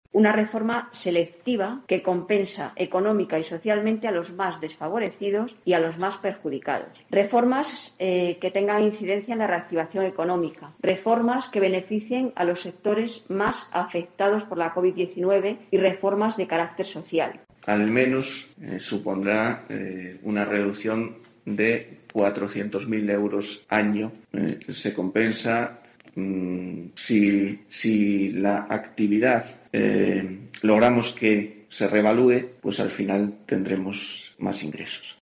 Escucha aquí las palabras de la concejala de Hacienda, Mabel Fernández, y el alcalde, Olegario Ramón